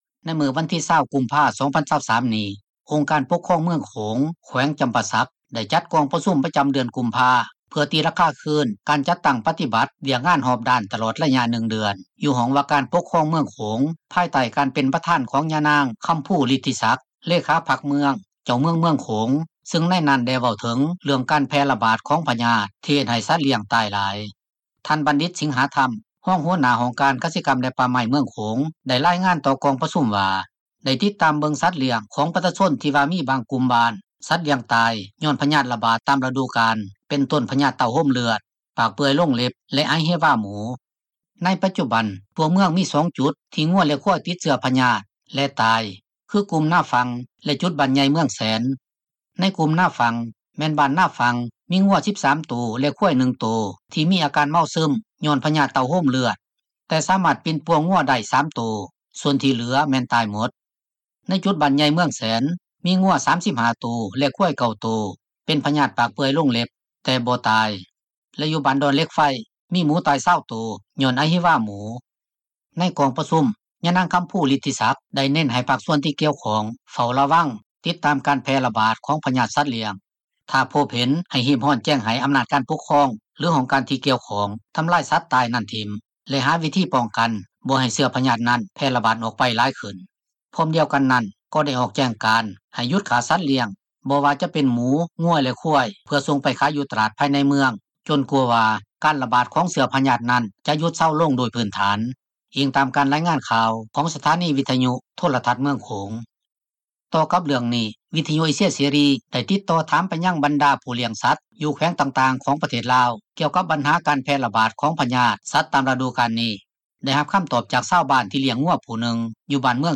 ດັ່ງທີ່ຊາວບ້ານຜູ້ນີ້ ເວົ້າຕໍ່ວິທຍຸເອເຊັຽເສຣີ ໃນມື້ວັນທີ 23 ກຸມພານີ້ວ່າ: